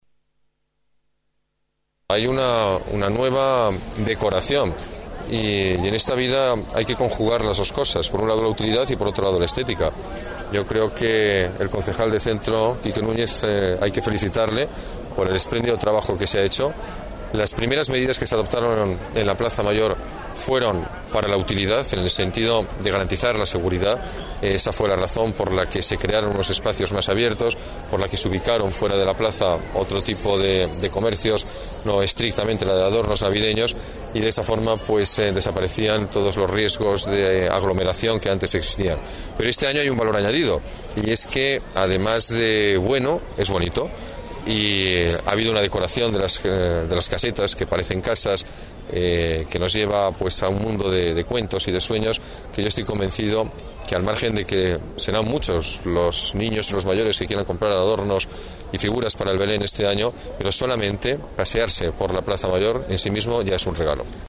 Nueva ventana:Palabras del alcalde, Alberto Ruiz-Gallardón.